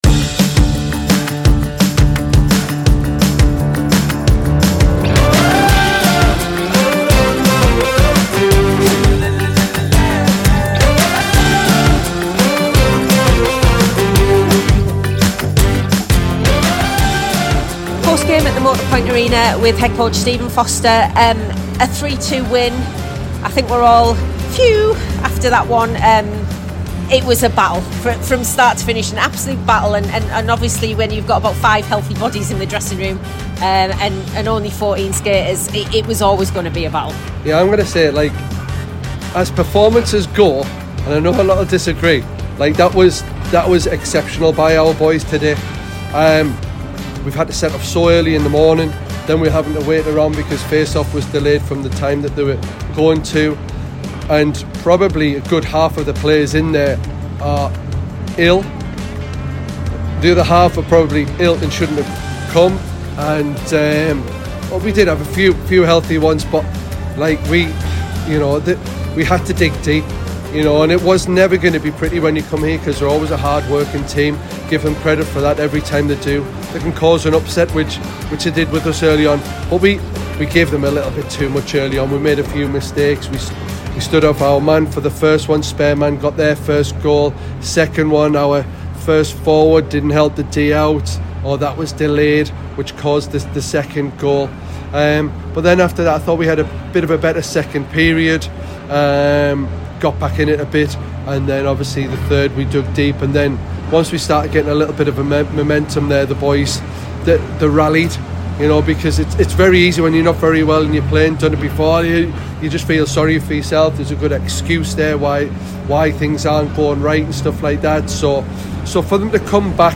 He talked post-game